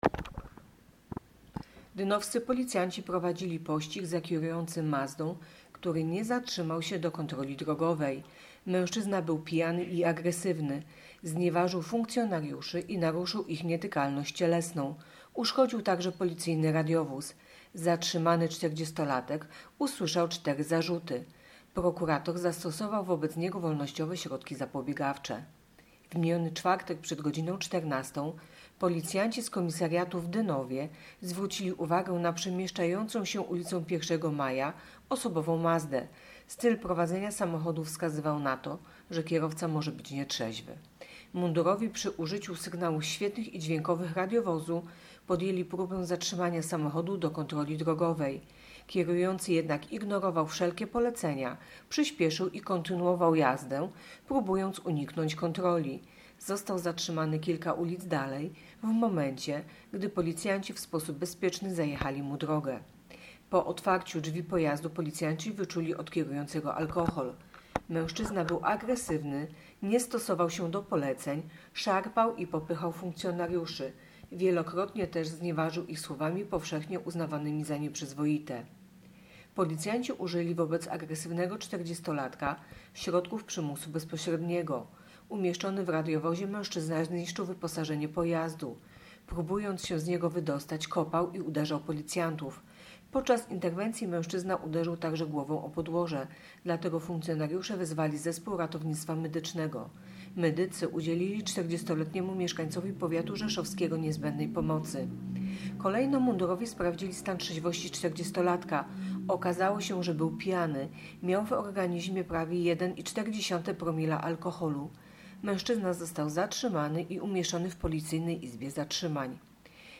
Nagranie audio Mówi